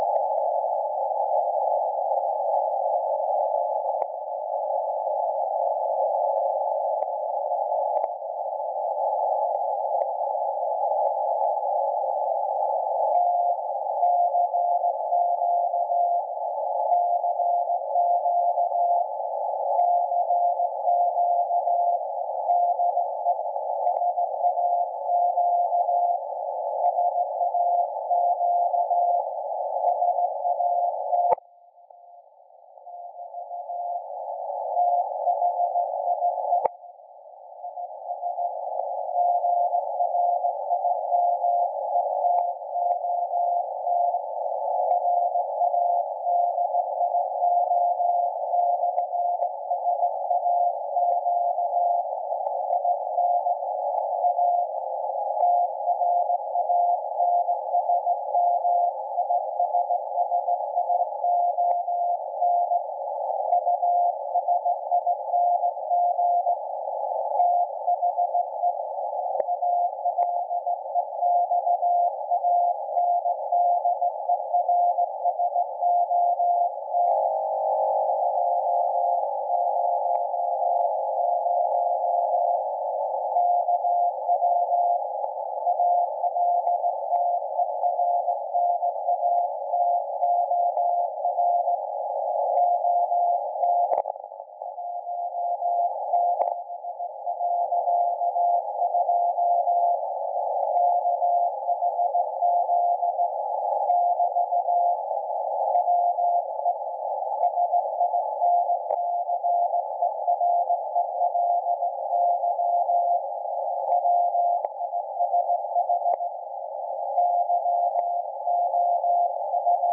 Rx: RFspace SDR-IQ
Ant: Wellbrook ALA-100M (in wrong direction, more E-W than N-S)
Some QRM from a nearby TV set.